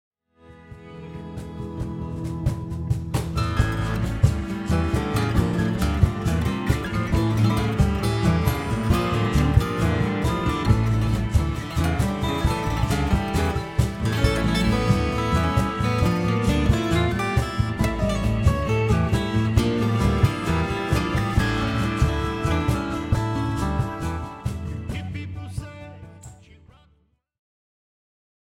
Here he is in my home studio.